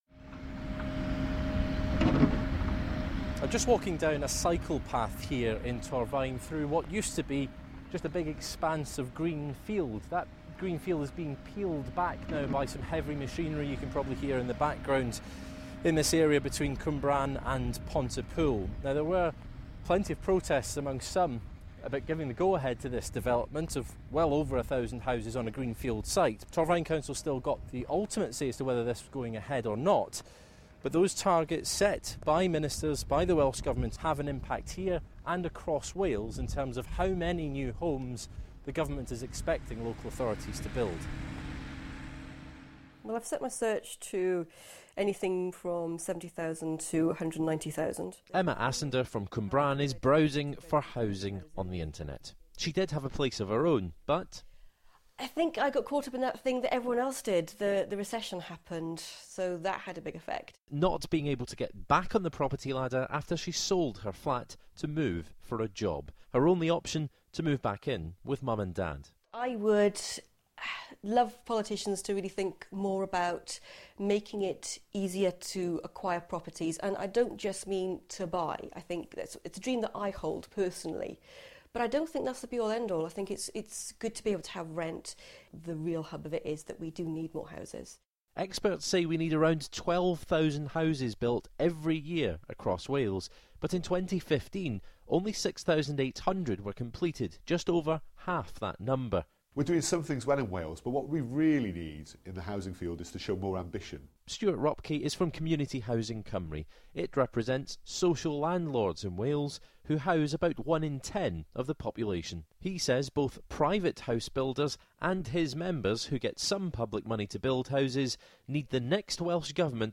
Hear one woman's story of trying to get on the housing ladder and what the Welsh Political parties promise to do about the problem.